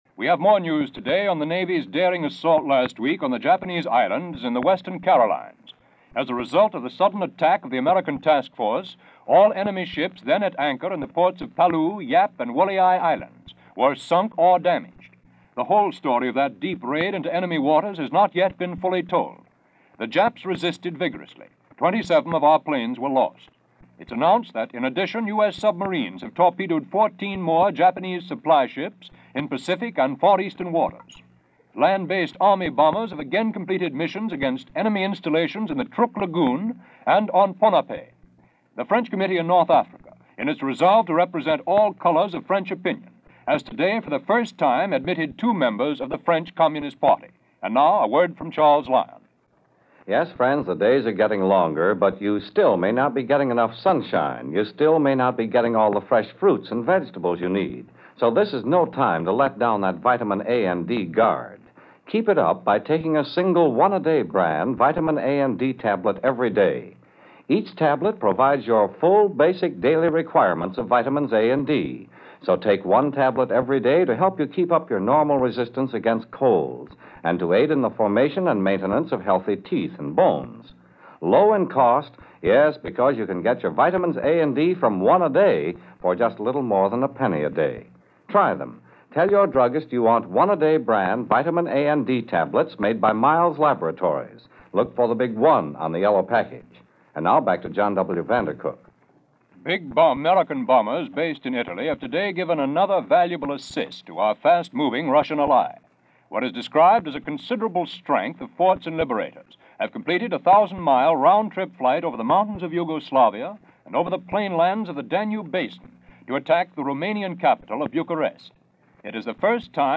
World At War - Looking For Signs - Making Preparations - Gazing At Future - April 4, 1944 - NBC News Of The World.